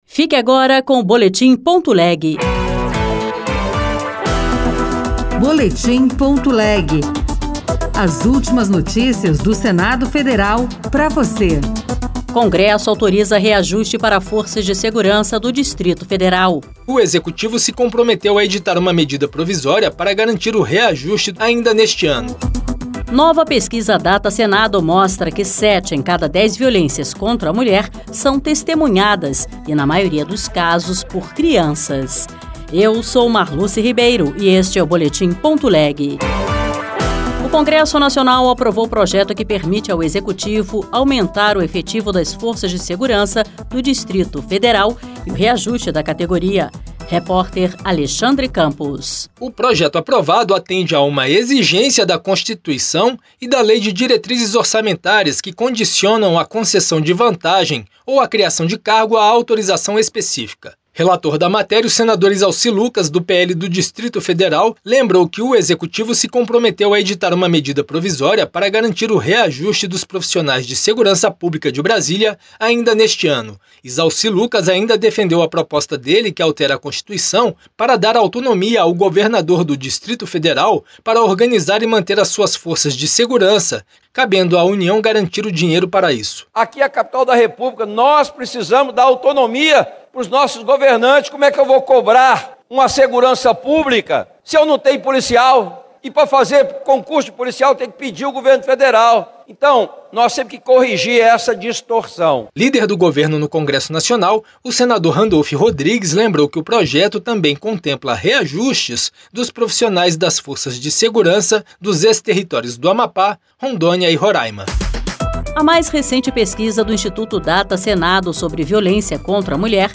RadioAgência Senado